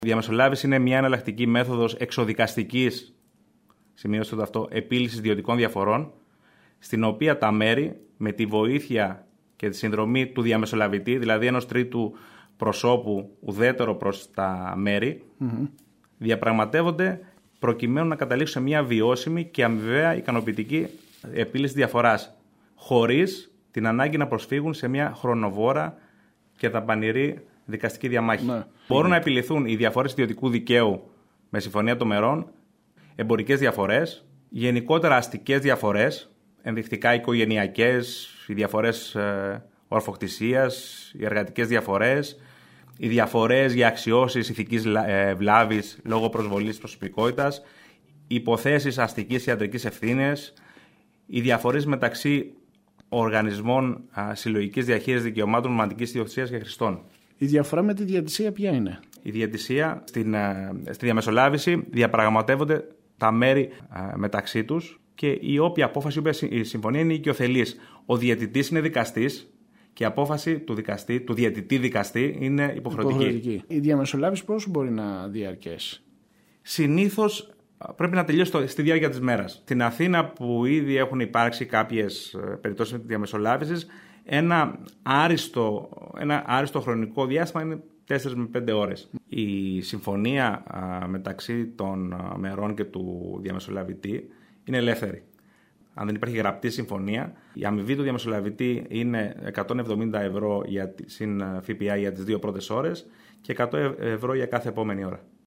μιλώντας στο σταθμό μας